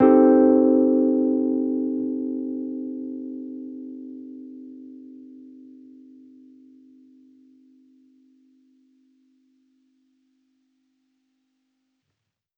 Index of /musicradar/jazz-keys-samples/Chord Hits/Electric Piano 1
JK_ElPiano1_Chord-Cm6.wav